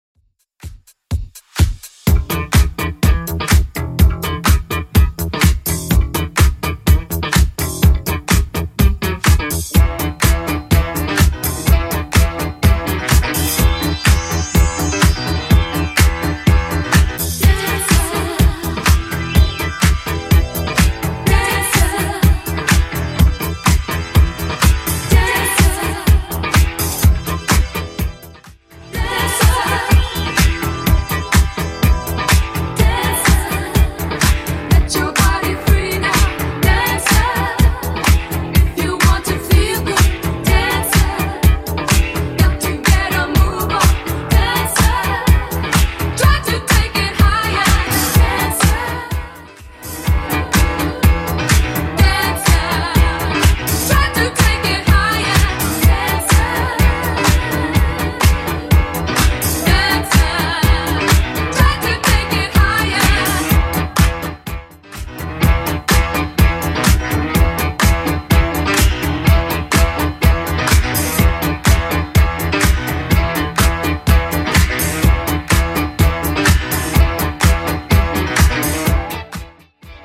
Genre: 80's
BPM: 120